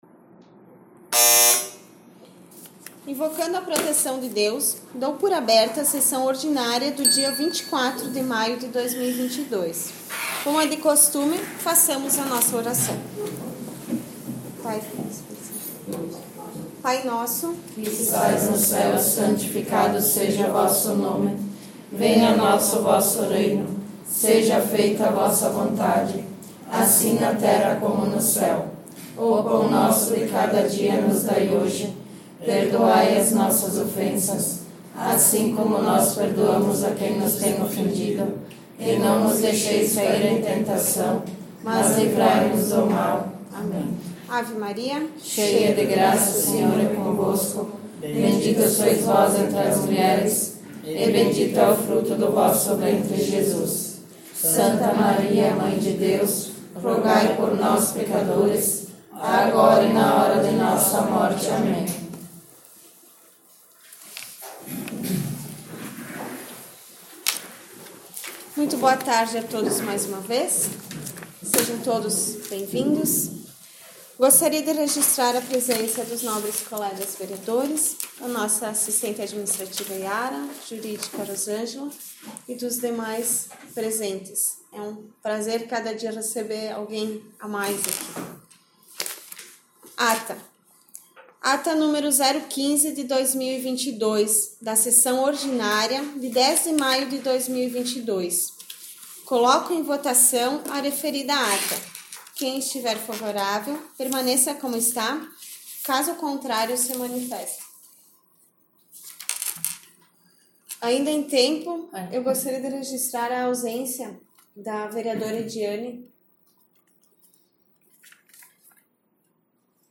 14 - Sessão Ordinária 24 maio